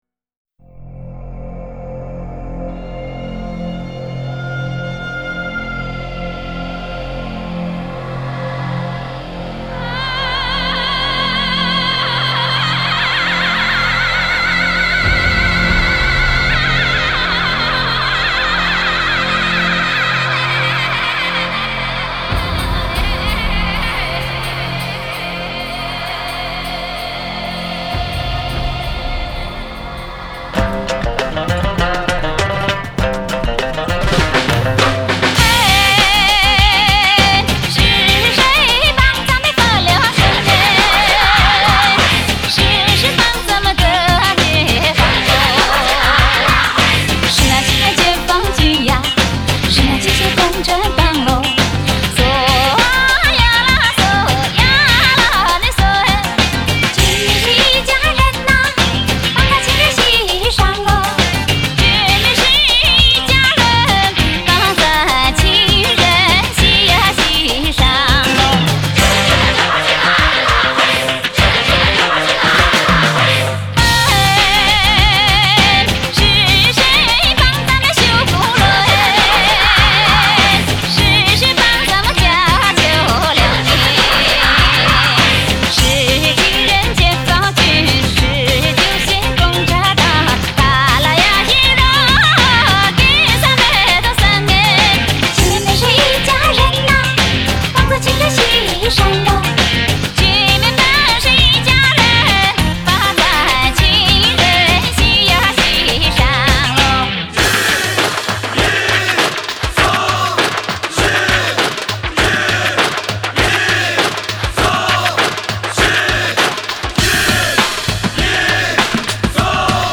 Жанр: Modern Traditional / Chinese pop / Tibetan folk